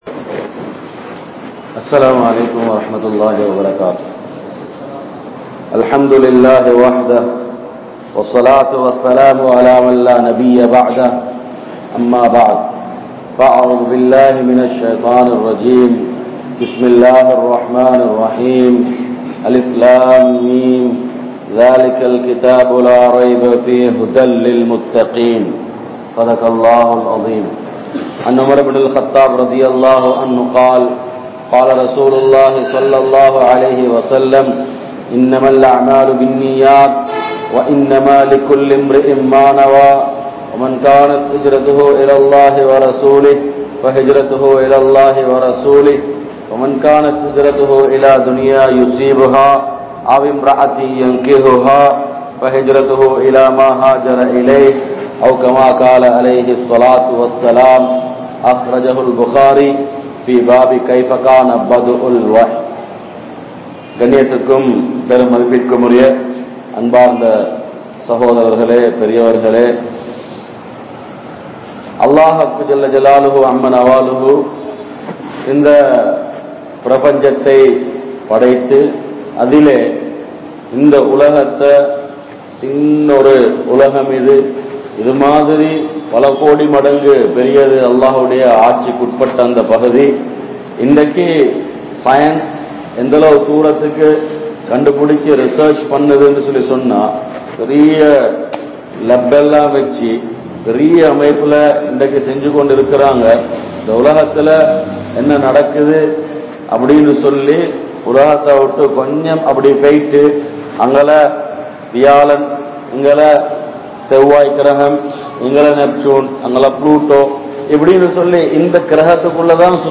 Suvarkaththin Vaalifarhal(சுவர்க்கத்தின் வாலிபர்கள்) | Audio Bayans | All Ceylon Muslim Youth Community | Addalaichenai